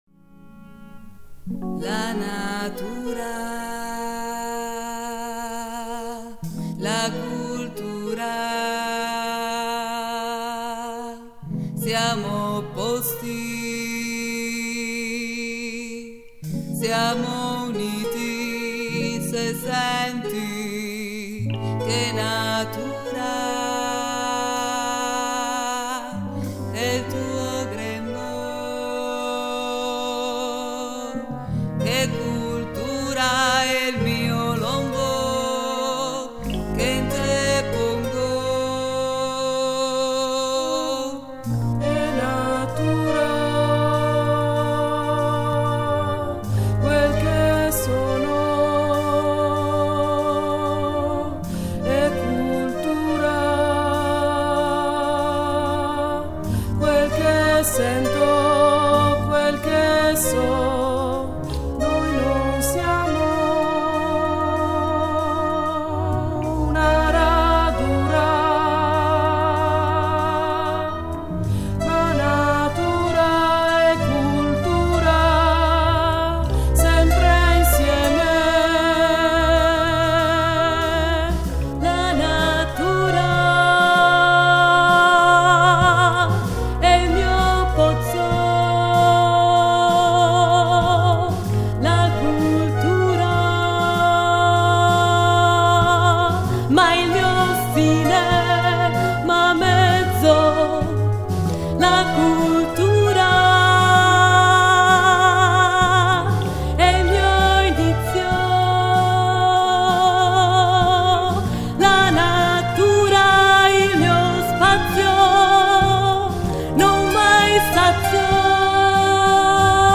Melologo e pantomima musicale